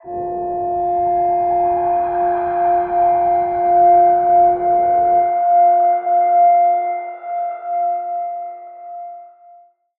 G_Crystal-F6-mf.wav